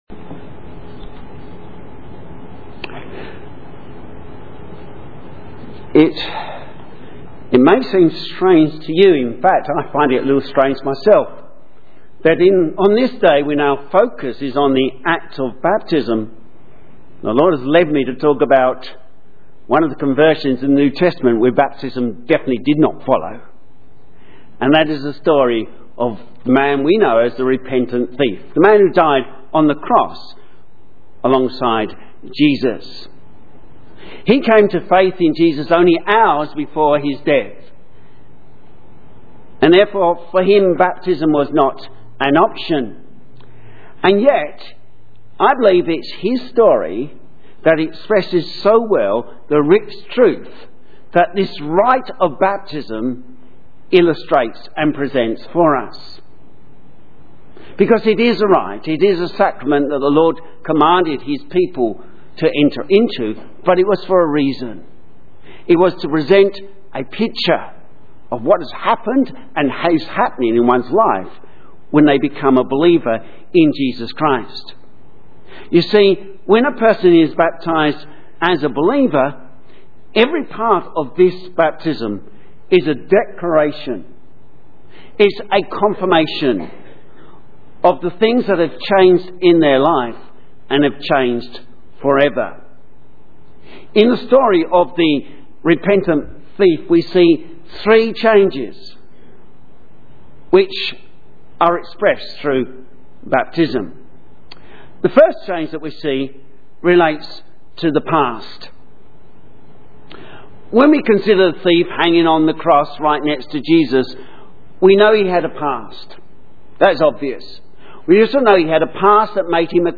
Sermon
Baptism sermon The Final Hope Luke 23:26-49 Synopsis In this sermon preached at the baptism of a young man we look at the story of the repentant thief on the cross to show the three changes in a person life that baptism proclaims Keywords Baptism.